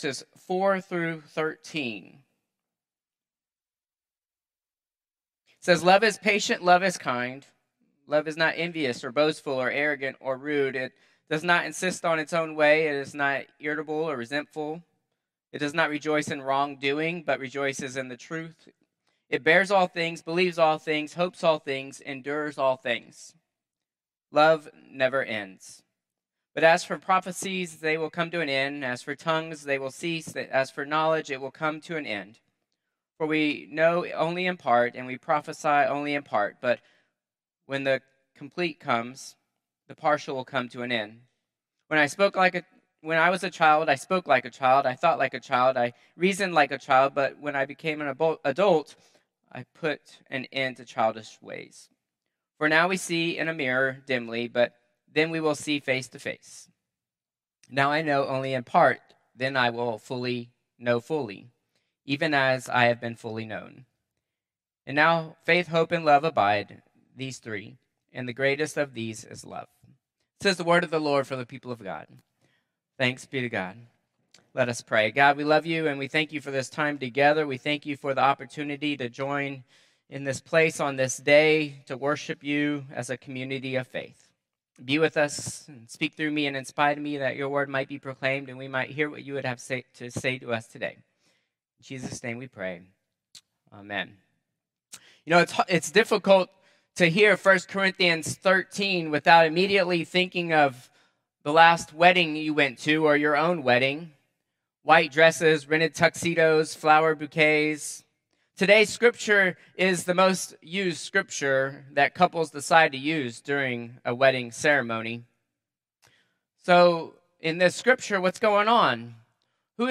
Contemporary Service 5/11/2025